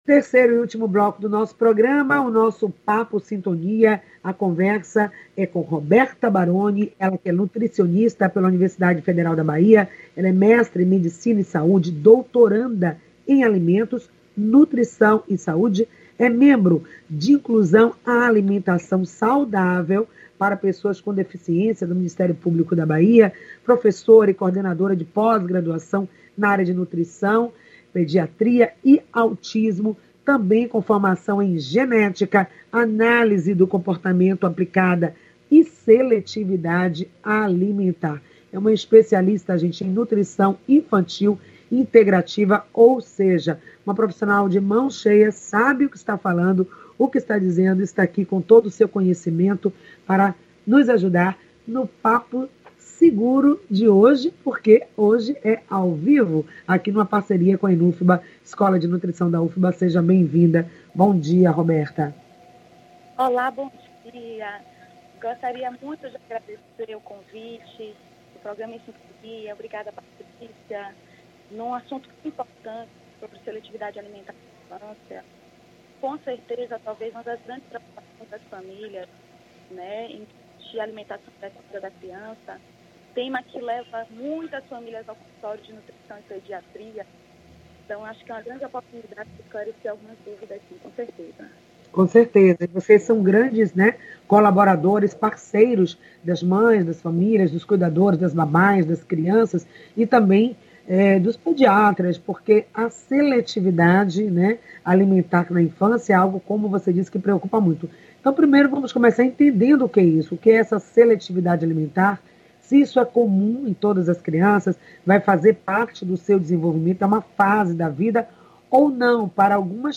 Áudio da entrevista:
O programa acontece de segunda à sexta, das 9 às 9:55h, pela Rádio Excelsior AM 840.